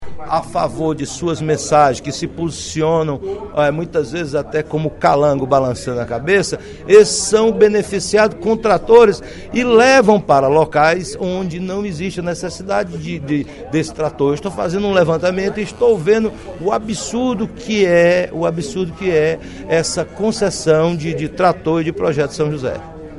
O deputado Roberto Mesquita (PV) disse, na sessão plenária desta quinta-feira (23/02) da Assembleia Legislativa, que a distribuição de tratores para a agricultura familiar no Ceará obedece a critérios políticos e não técnicos.